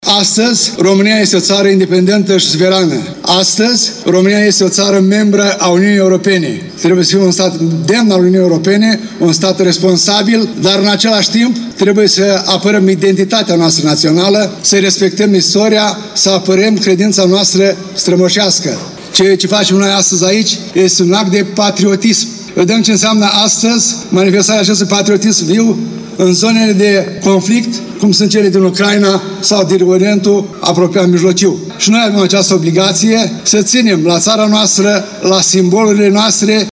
Ziua Națională a fost marcată în municipiul Suceava printr-o ceremonie festivă și o paradă militară pe bulevardul Ana Ipătescu.
Primarul municipiului Suceava ION LUNGU a spus că Marea Unire trebuie prețuită și dusă mai departe generațiilor viitoare.